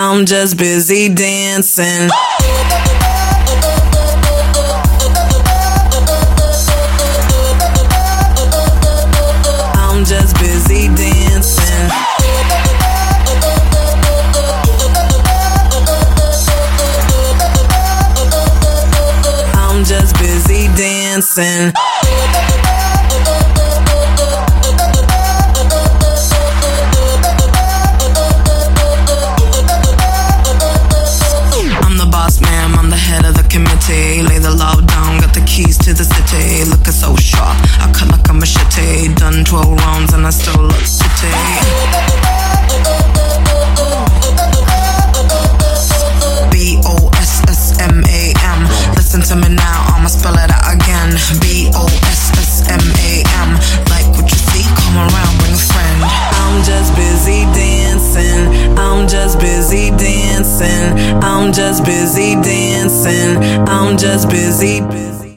• Качество: 147, Stereo